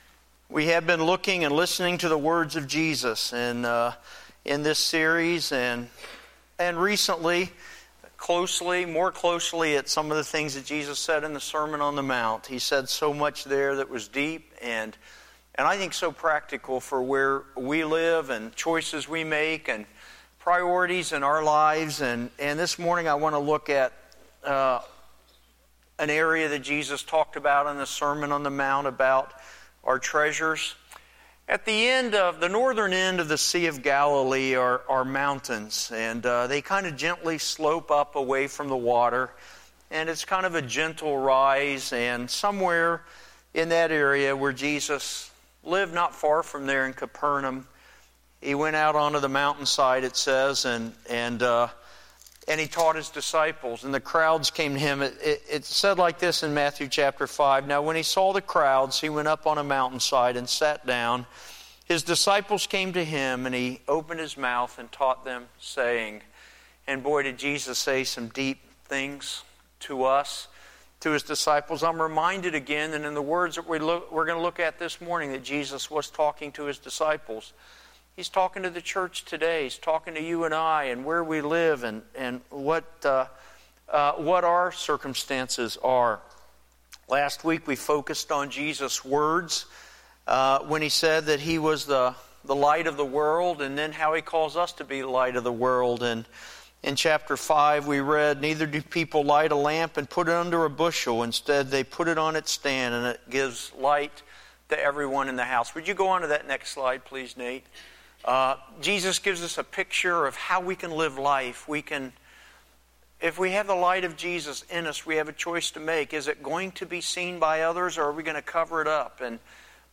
2-8-15-9am-Sermon.mp3